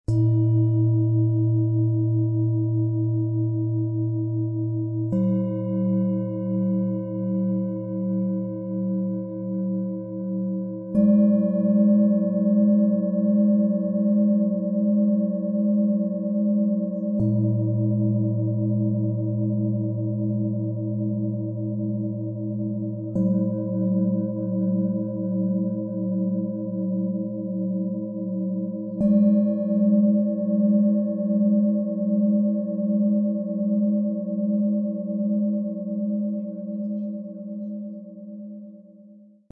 Die Vibration ist intensiv - ideal für alle, die bewusst ins Spüren kommen möchten.
Im Sound-Player - Jetzt reinhören können Sie den Original-Ton genau dieser Schalen des Sets selbst anhören. Wir haben einen etwas härteren Klöppel ausgewählt, um Raumklang zu erzeugen
Das Schalenset besteht aus den folgenden Klangschalen:
Tiefster Ton: Mond, Mond
Mittlerer Ton: Biorhythmus Geist, DNA
Höchster Ton: Uranus, Saturn